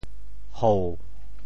“戽”字用潮州话怎么说？
戽 部首拼音 部首 户 总笔划 8 部外笔划 4 普通话 hù 潮州发音 潮州 hou3 文 中文解释 戽 <名> (形声。